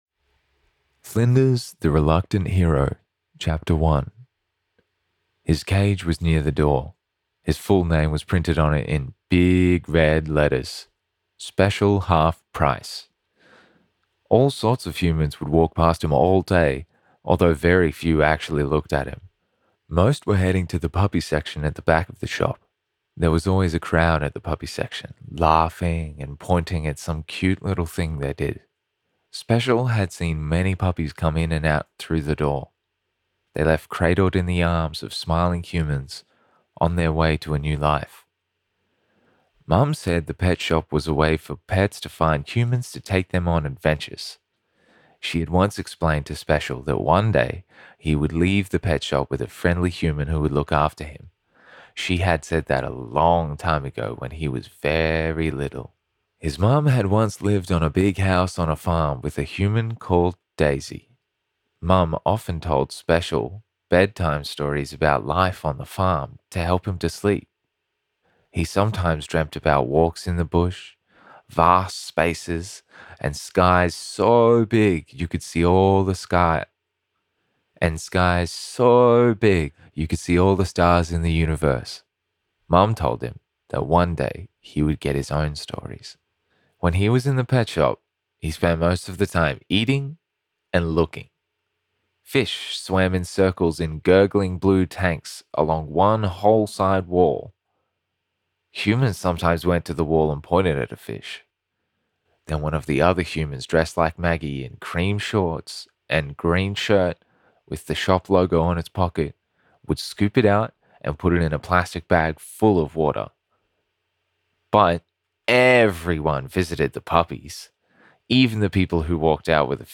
Children's Book - Flinders the Reluctant Hero [SOFT AUSTRALIAN, CHARACTER]
Young Adult